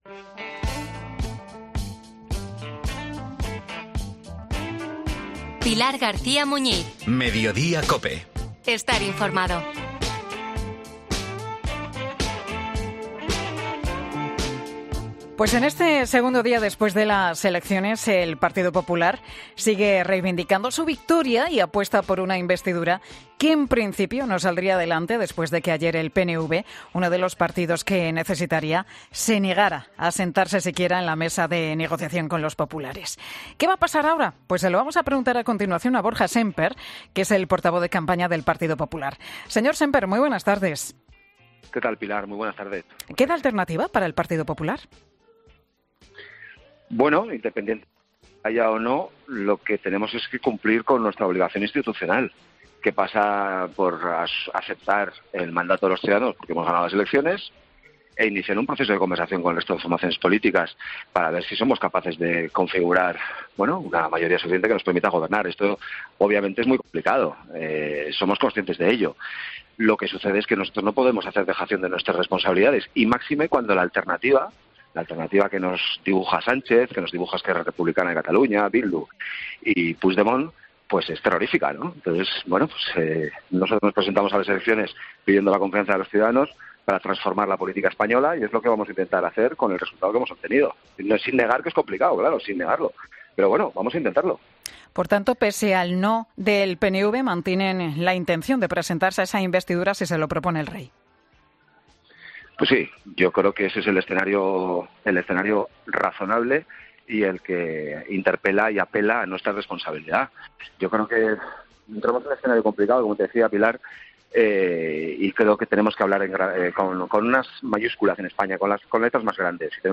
Borja Sémper admite en Mediodía COPE que Feijóo va a hacer todo lo posible para formar gobierno